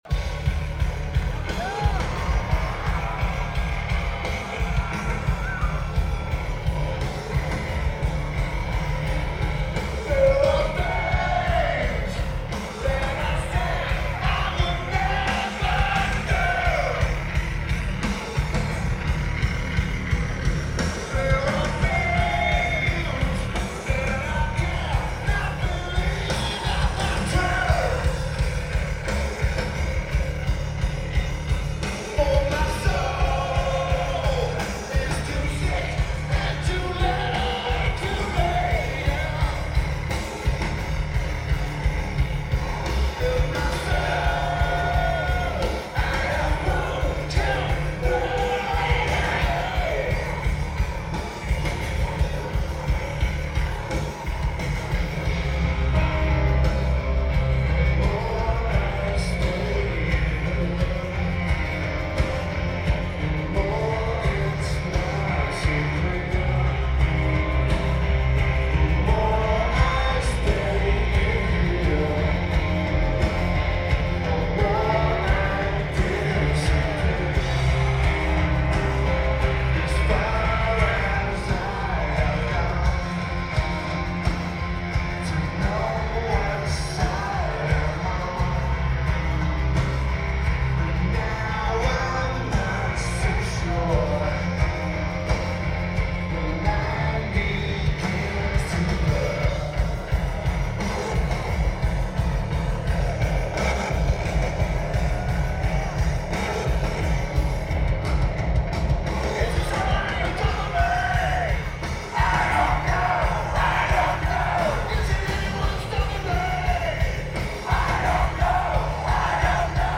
House of Blues
Lineage: Audio - AUD (ATu853s + AT8532s + Edirol R09)